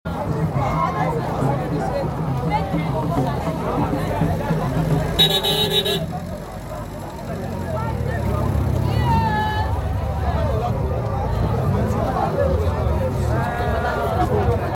HAPPENING NOW: Osun Devotees, Traditionalists, Indigenes and Residents of Osogbo, Tourists from far and wide, among others gather to celebrate the grand finale of the 2025 Osun Osogbo Festival .